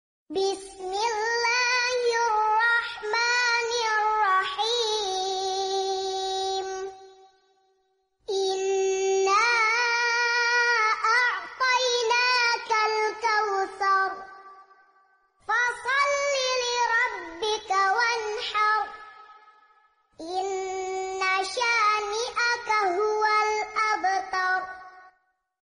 Lipsync Murottal bacaan Anak metode ummi